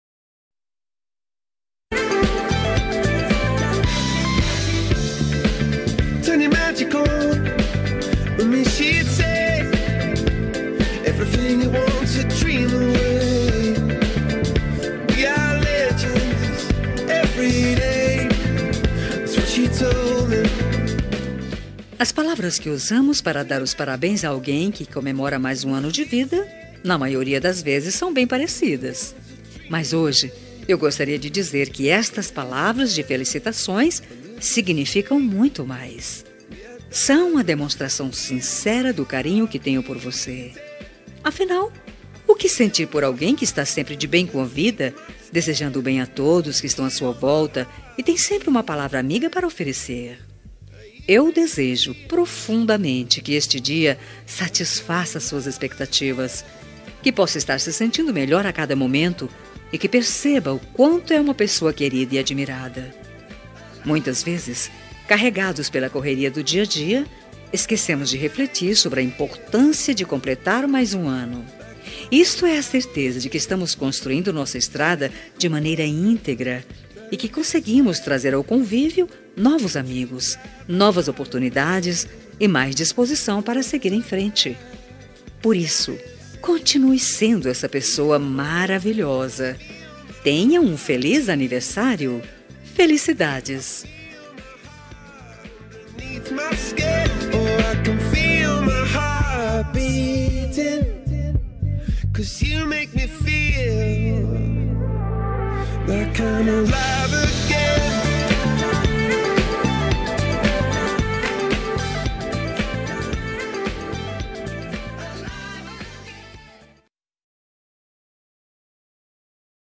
Telemensagem de Aniversário de Pessoa Especial – Voz Feminina – Cód: 202217